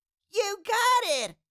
Cartoon Little Child, Voice, You Got It Sound Effect Download | Gfx Sounds
Cartoon-little-child-voice-you-got-it.mp3